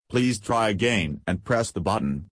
voice-search-sound.ogg